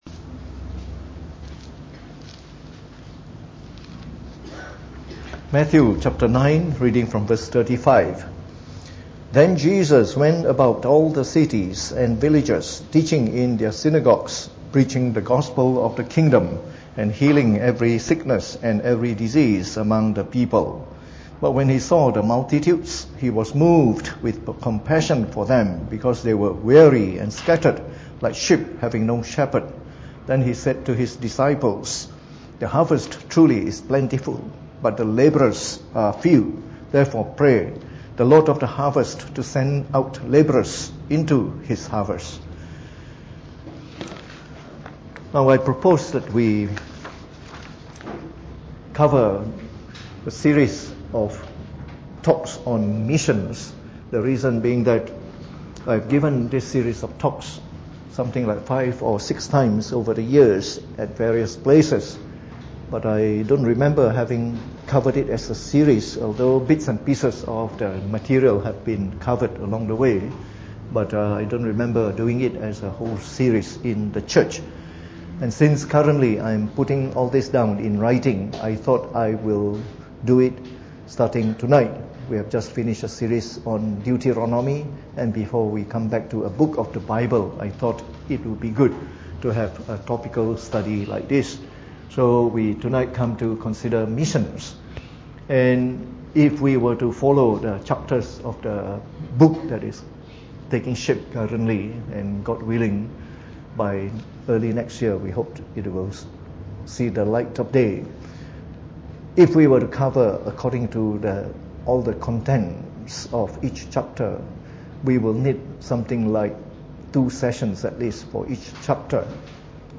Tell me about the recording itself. Preached on the 7th of November 2018 during the Bible Study, from our series on Missions.